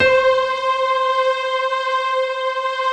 SI1 PIANO0BR.wav